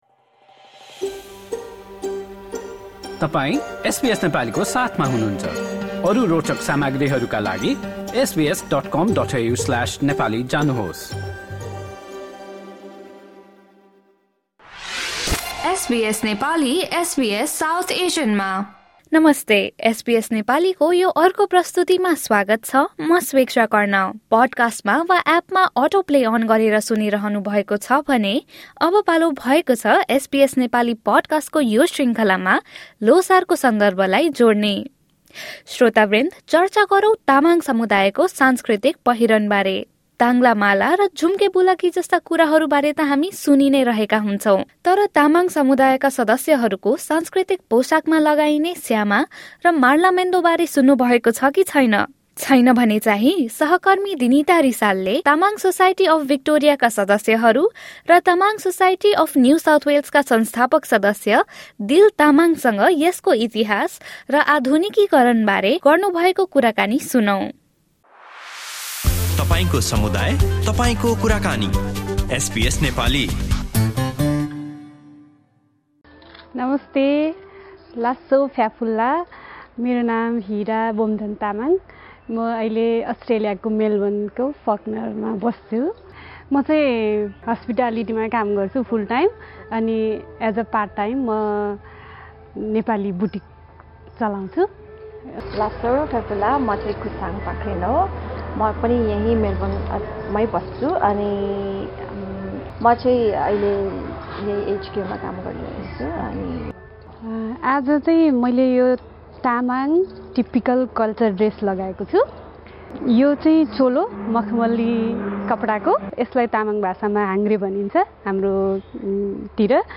र आधुनिकीकरणबारे एसबीएस नेपालीसँग गर्नुभएको कुराकानी सुन्नुहोस्।